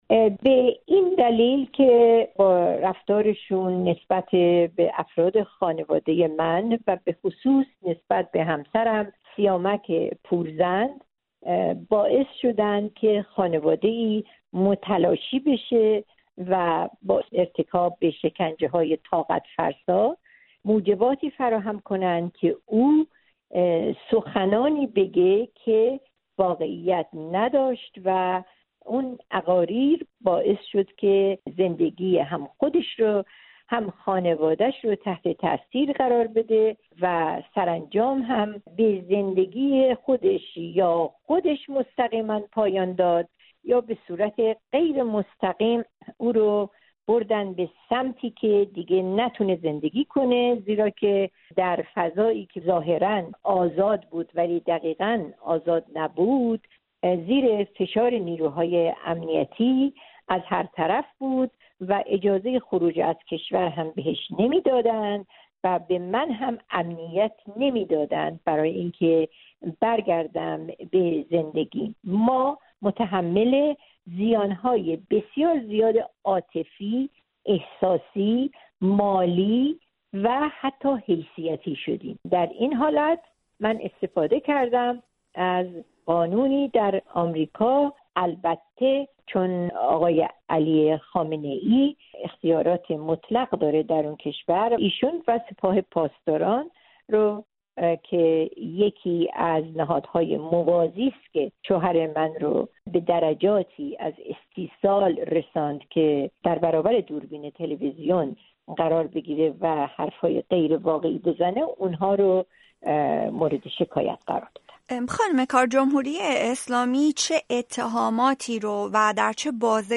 گفت‌وگوی